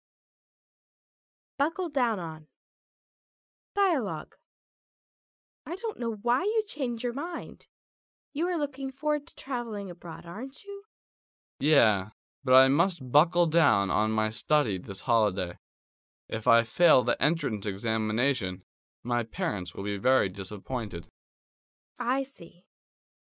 第一，迷你对话